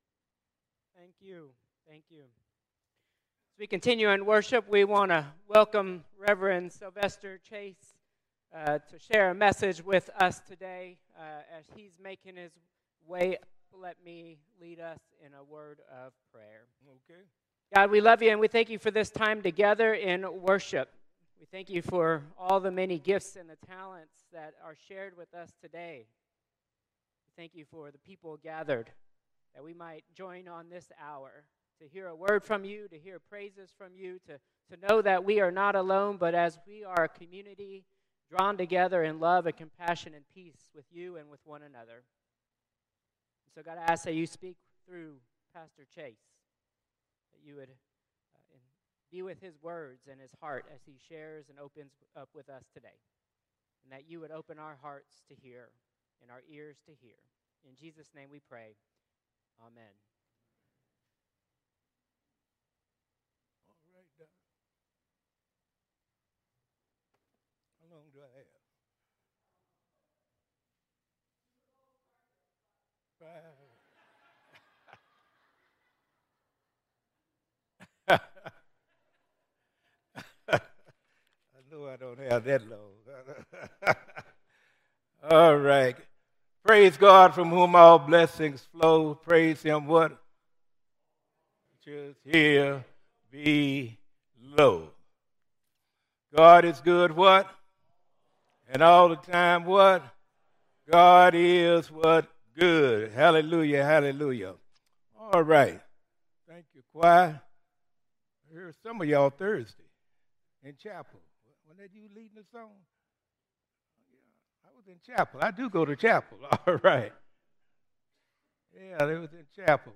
Combined Worship 2-8-2026